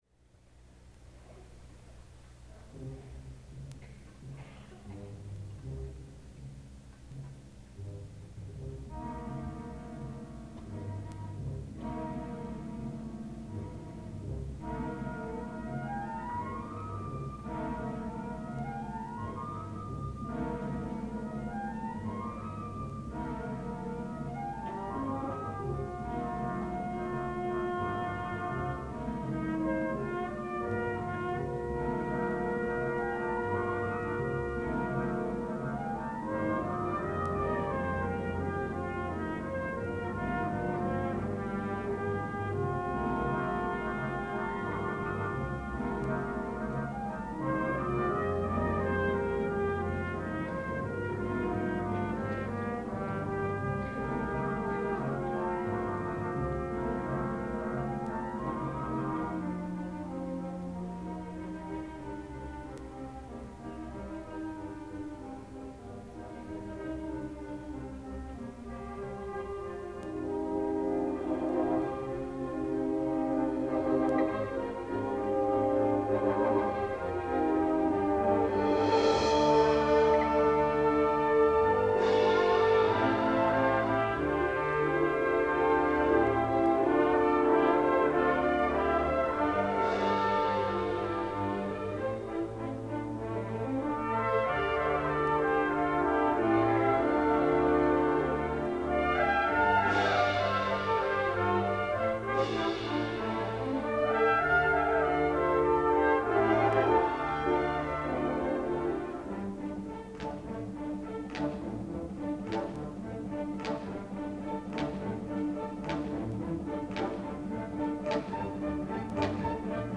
for Orchestra (1998)